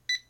零售 " 扫描仪哔哔声
描述：条码扫描器提示音
Tag: 扫描 扫描仪 蜂鸣